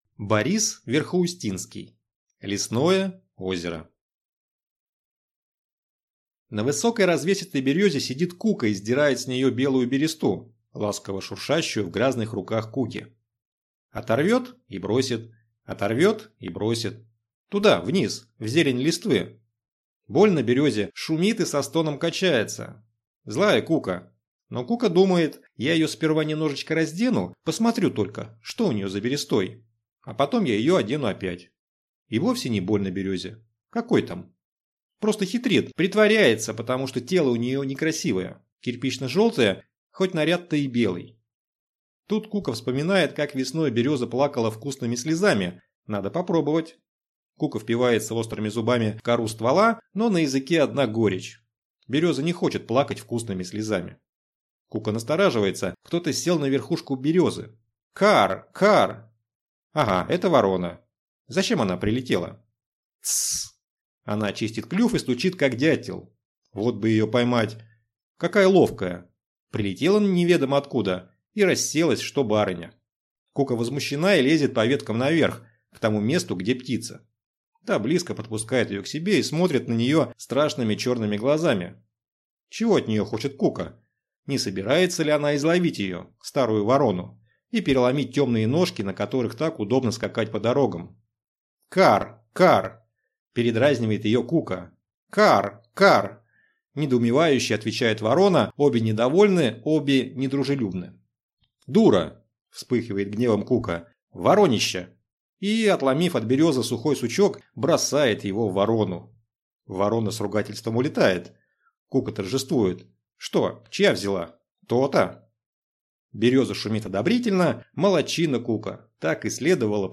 Аудиокнига Лесное озеро | Библиотека аудиокниг